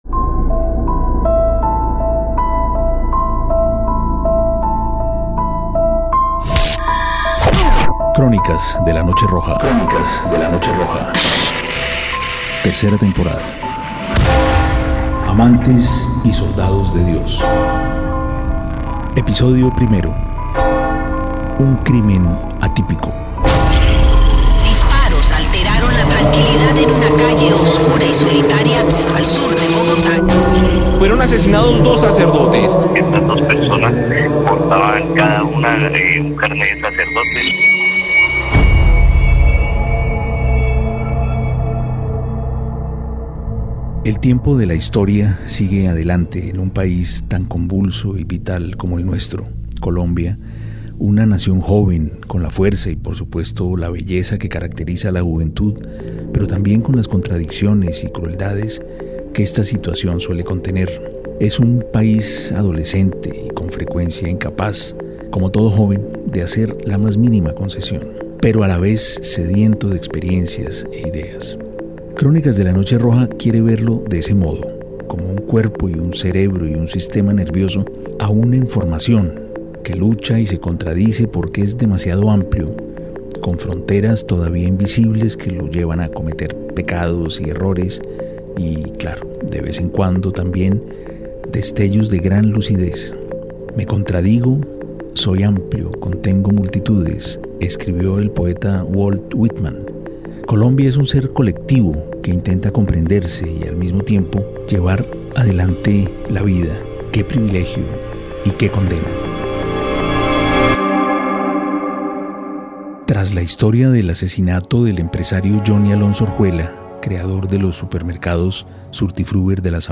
Narrada y escrita por Santiago Gamboa, escúchala gratis en RTVCPlay.